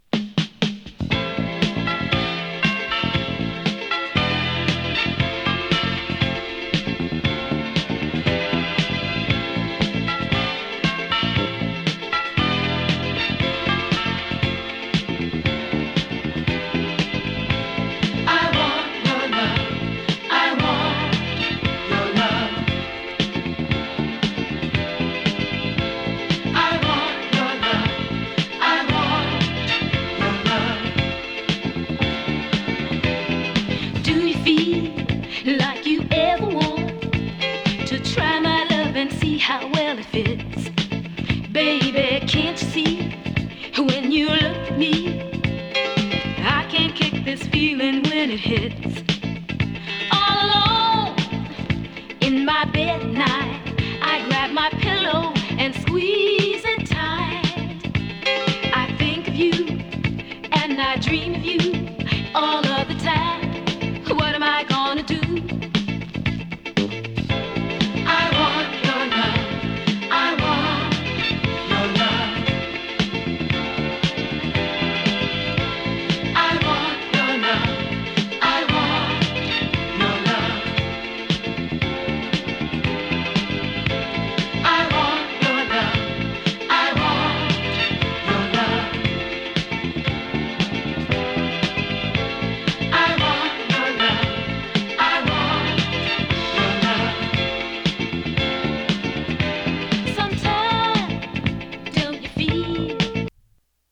哀愁 ディスコ ソウル
キャッチーなサビの哀愁ディスコ・クラシック！